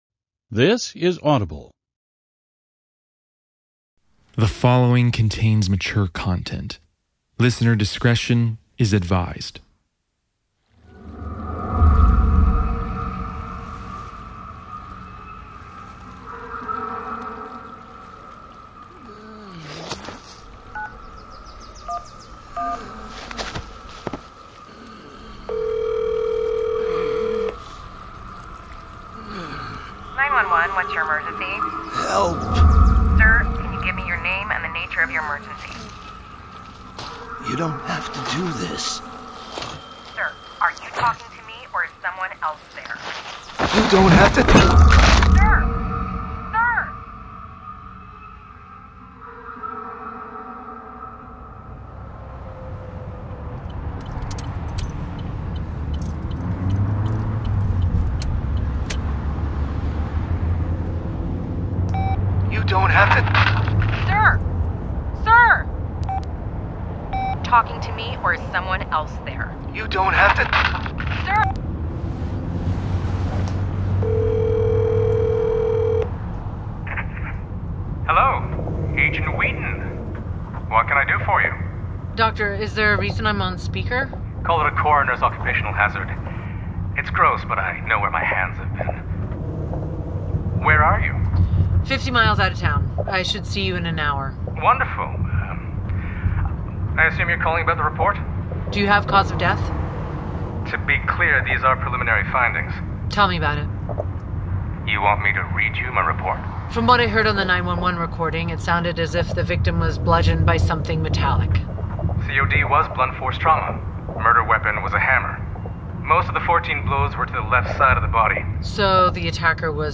Michael Peterson – Jakob’s Point Audiobook